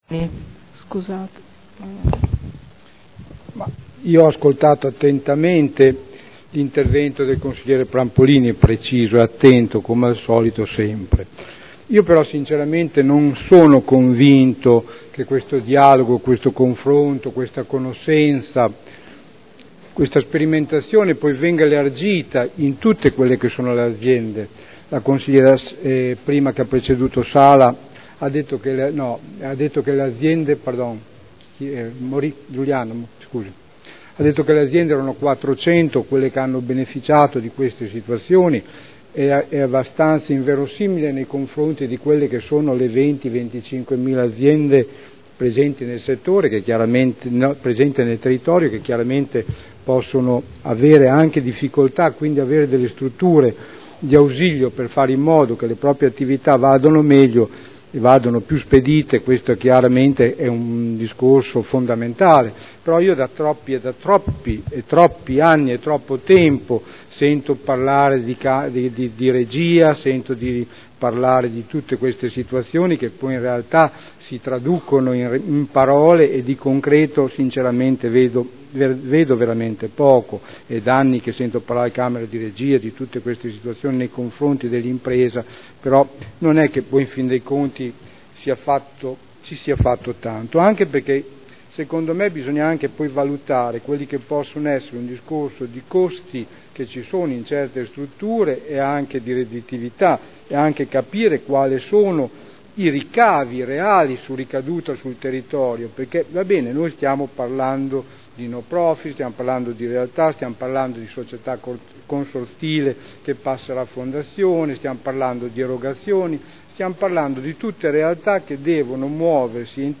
Seduta del 22/12/2011. Dibattito su proposta di deliberazione. Trasformazione di Democenter-Sipe da Società Consortile a Responsabilità Limitata a Fondazione – Approvazione dello statuto